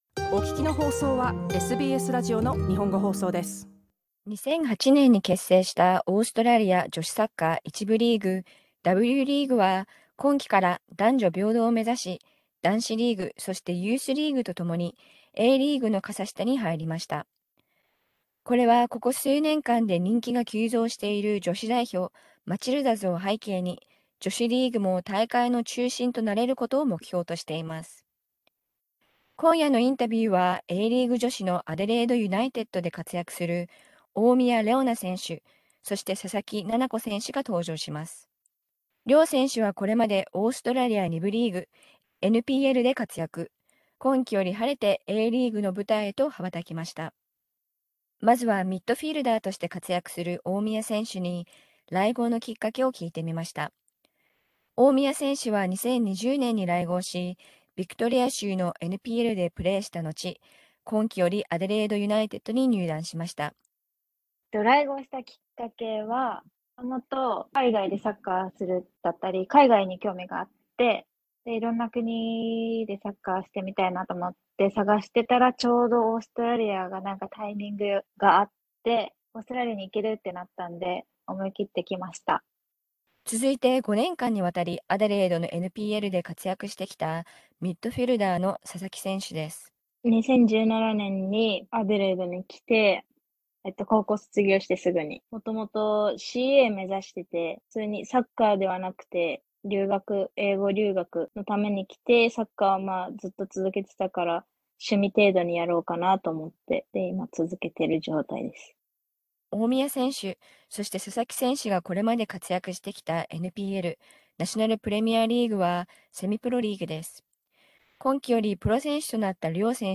またこのインタビューを収録した直後、アデレード・ユナイテッドの男子チームに、日本人ストライカー指宿洋史選手の加入が発表されました。